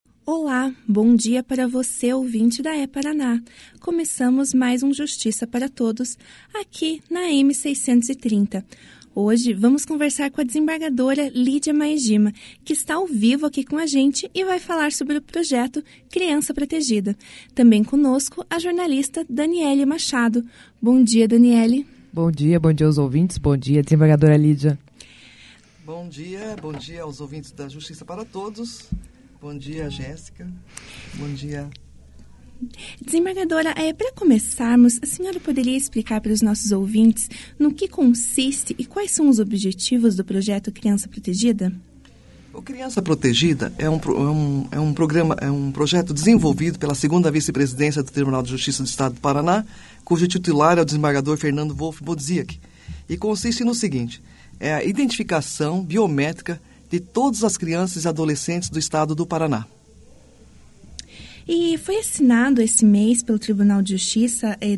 Entrevistado:
Desembargadora Lídia Maejima
aqui a entrevista na íntegra.